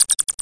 AMB_RATS.mp3